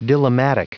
Prononciation du mot dilemmatic en anglais (fichier audio)
Prononciation du mot : dilemmatic